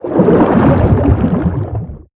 Sfx_creature_shadowleviathan_swimfast_05.ogg